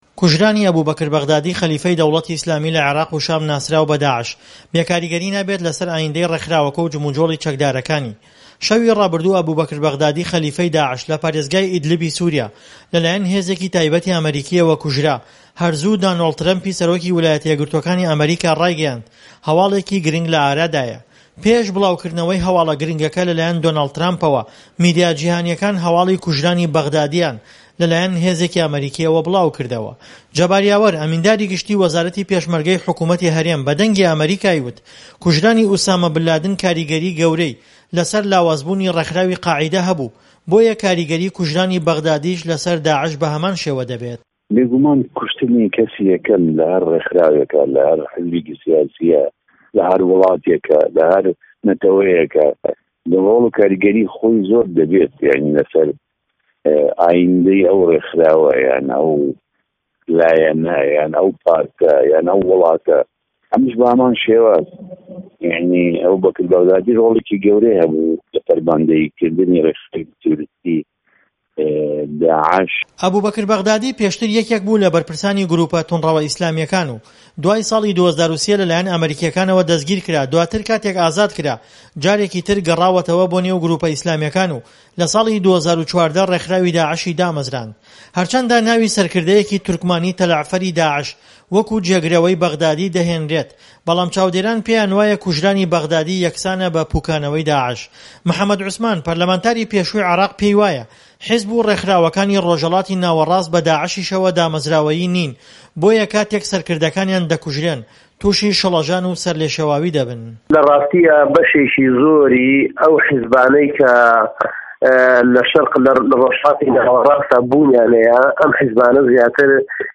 ڕاپۆرت - کوژرانی به‌غدادی